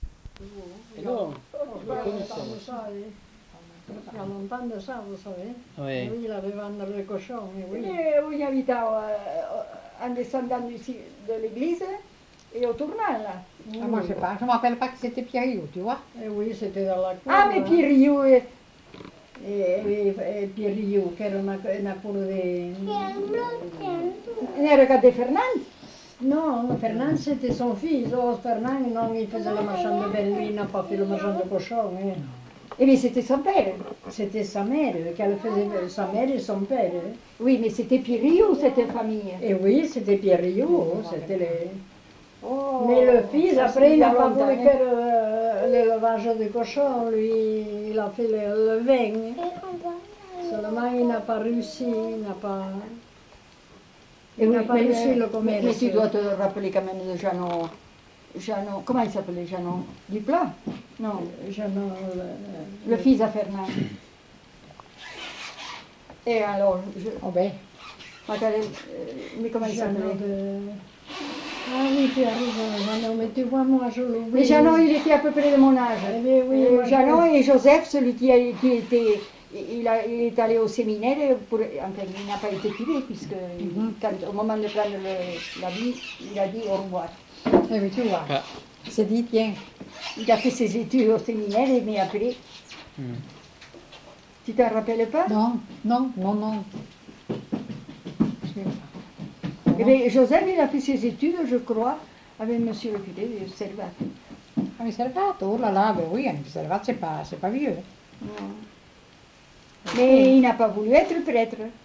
Lieu : Montjoie-en-Couserans
Genre : parole